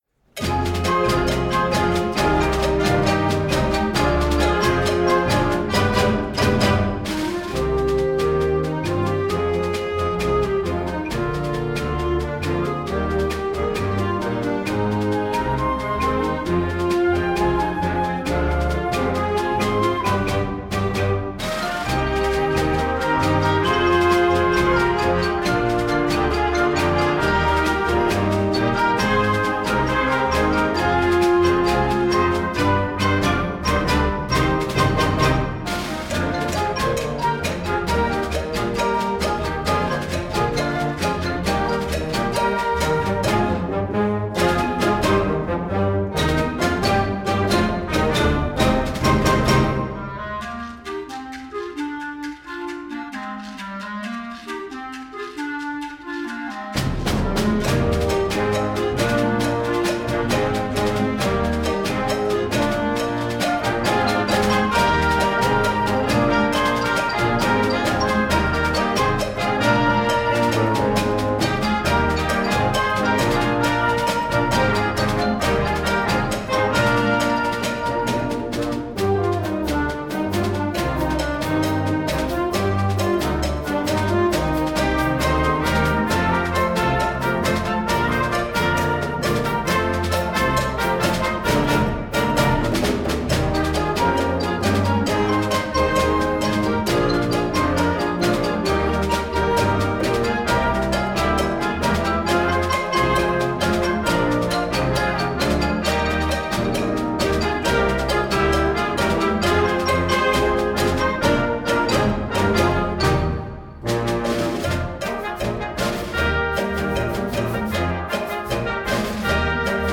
Gattung: Unterhaltungswerk für Jugendblasorchester
Besetzung: Blasorchester